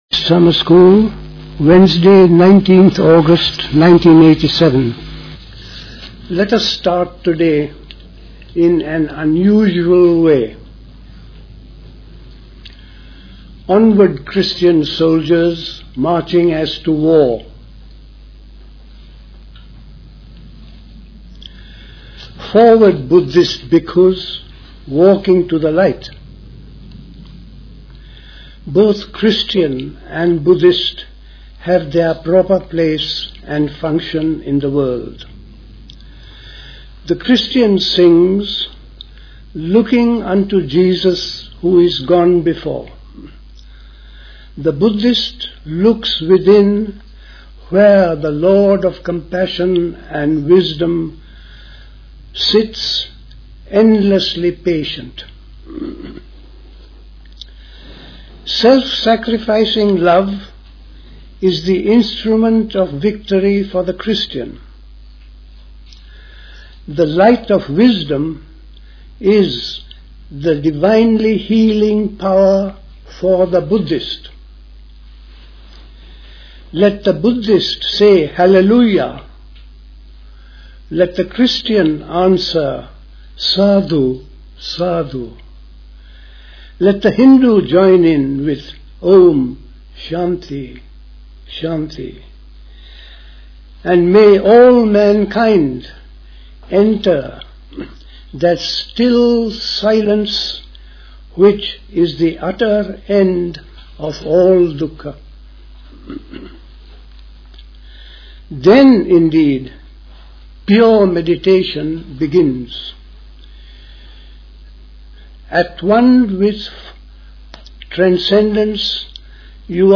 Recorded at the 1987 Buddhist Summer School.